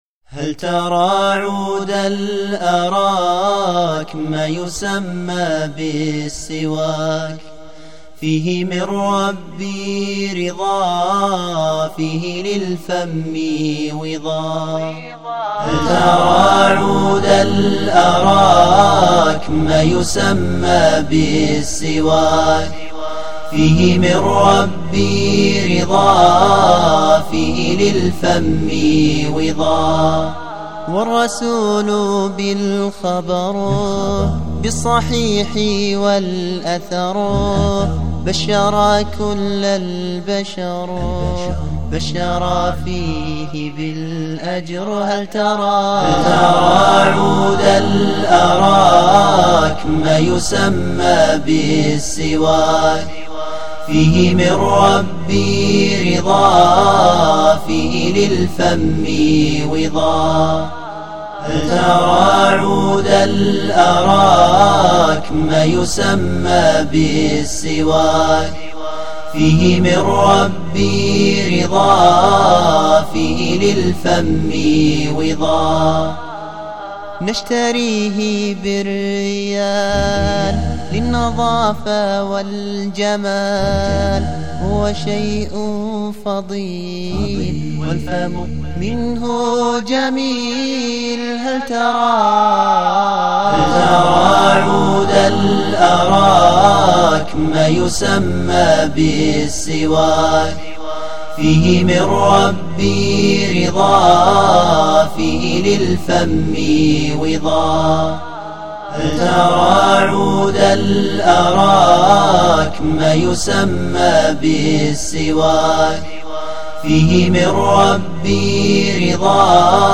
نشيد بصوتي .. من قديمي المستحدث ..
تم التسجيل في استديو الشفاء الصوتي بالرياض ..
انشوده رائعه وهادئه ..
دخلت الكورال فيها نطة شوي .. لكن مشيها